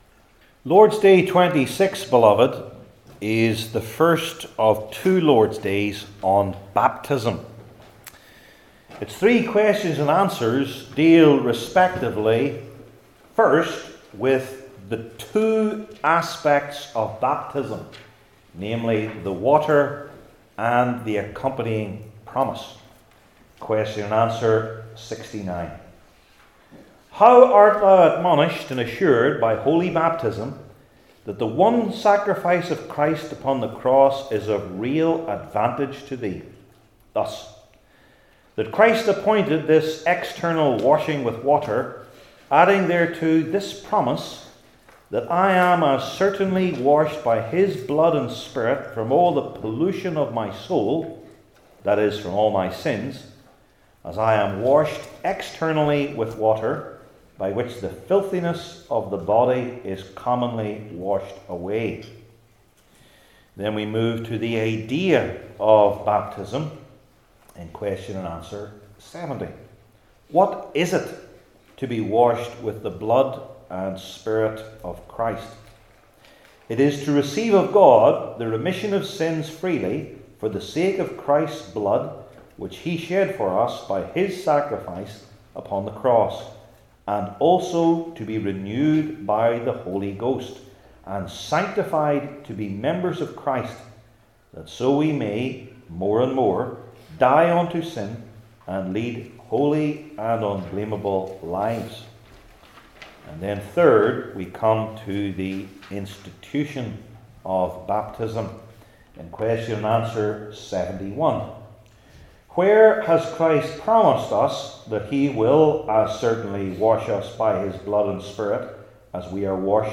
Ezekiel 16:1-22 Service Type: Heidelberg Catechism Sermons I. Its Great Necessity II.